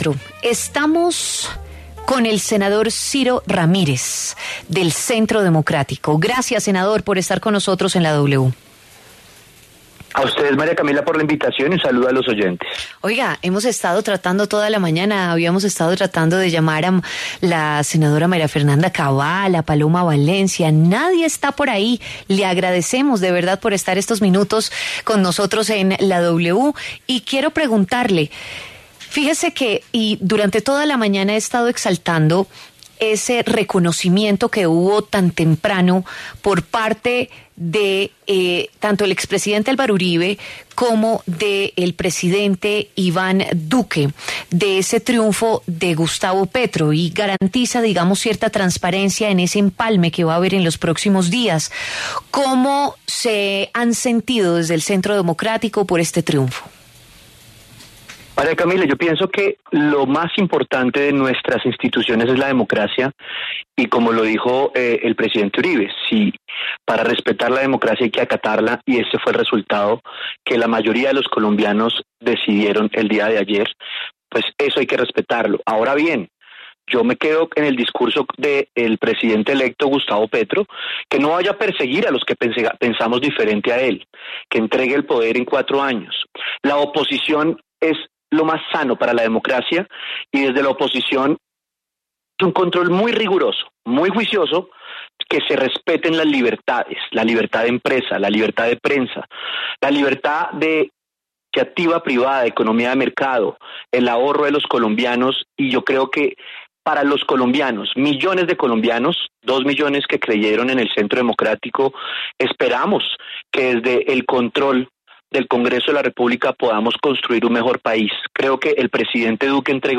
En conversación con el senador del Centro Democrático, Ciro Ramírez, dijo que, así como lo indicó Álvaro Uribe, desde el partido respetan la decisión que se tomó desde la democracia.